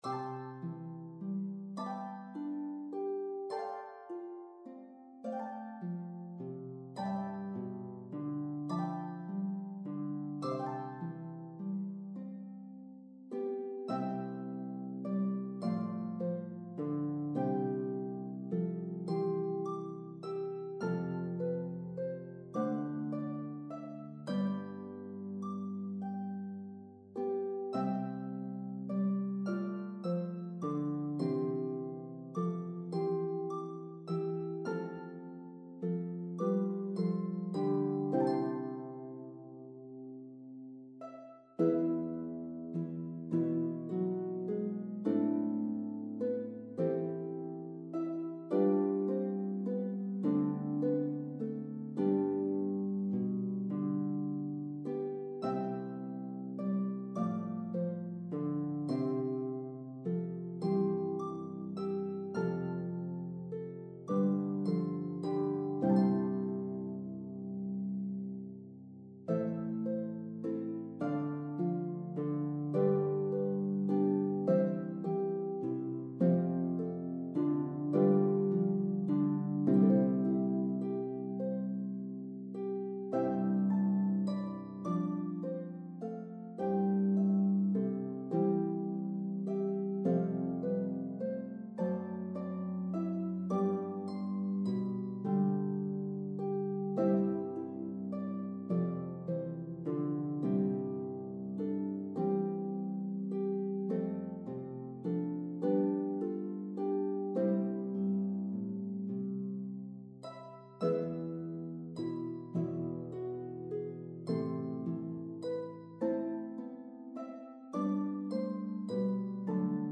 The melody is divided evenly between parts.